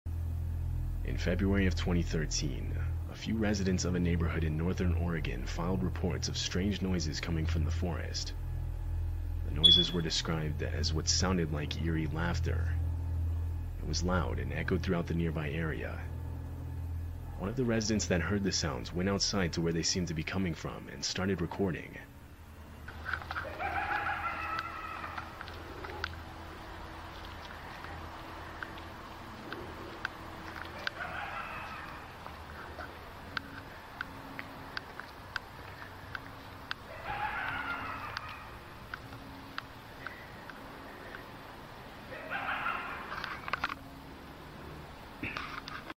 🌲 Strange laughter in the sound effects free download
🌲 Strange laughter in the forest? Watch what happened next in northern Oregon in 2013!